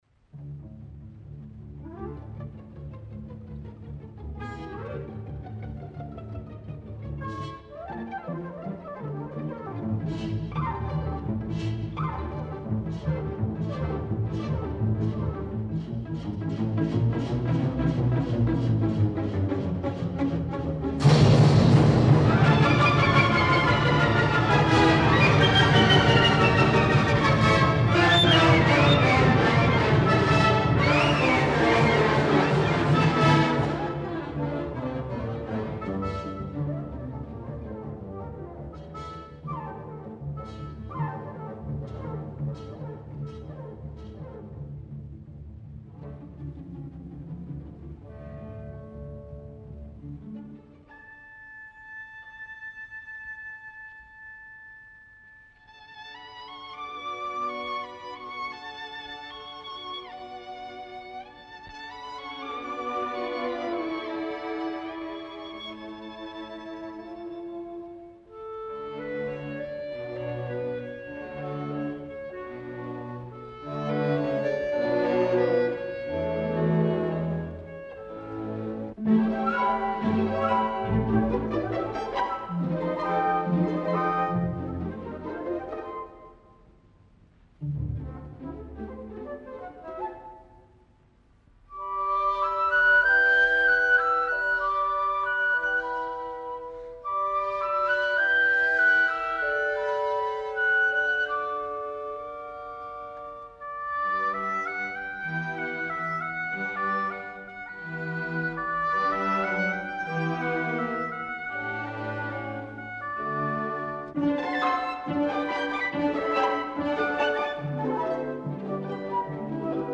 This piece, originally for piano, was orchestrated by conductor Anton Seidl for the New York Philharmonic as part of a work he called Norwegian Suite.
This recording was made in Moscow in 1949, with Nikolai Golovanov leading the Orchestre Symphonique de la Radio de l’URSS.
Nikolai Golovanov
Orchestre Symphonique de la Radio de l’URSS